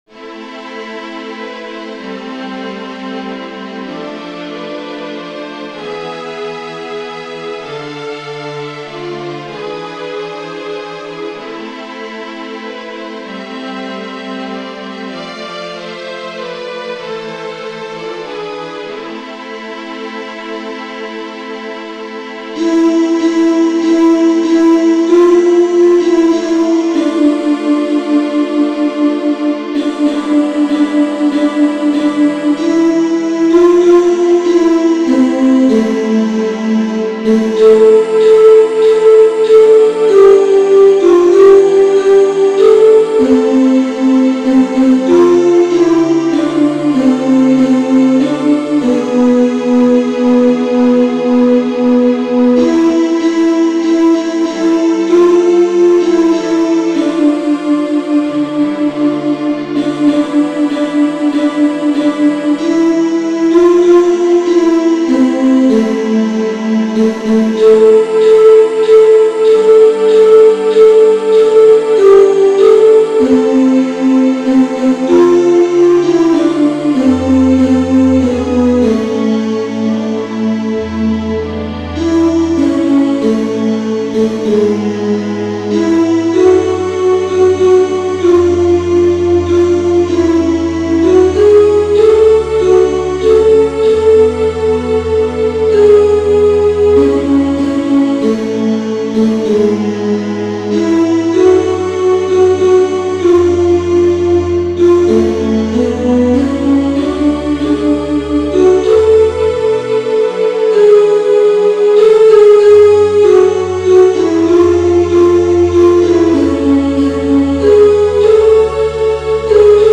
piano Alto  |  Alto 2